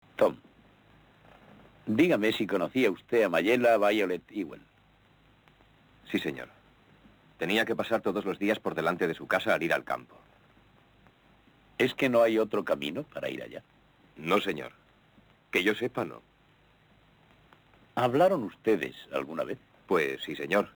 SONIDO (VERSIÓN DOBLADA)
El audio doblado al castellano, también se presenta en formato monoaural a unos pobres 96 Kbps. y resulta muy inferior en calidad a la versión original.
La dinámica es similar a la de la pista inglesa, pero la castellana presenta un constante y destacable ruido de fondo durante casi todo el metraje, que no se limita al habitual siseo sino que provoca además un efecto tipo chisporroteo que puede llegar a resultar bastante molesto.
Tanto el DVD de Universal como del de Suevia tienen el mismo doblaje aunque en el caso de Suevia, estaba presentado en un falso Dolby Digital 5.1. En la práctica, ambas pistas dobladas son iguales en calidad.